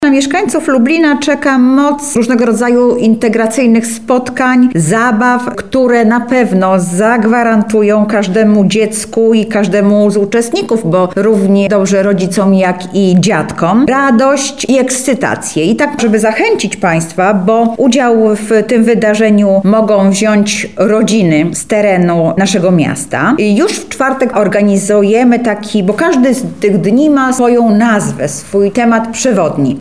O obchodach mówi Anna Augustyniak, zastępca prezydenta miasta Lublin do spraw społecznych.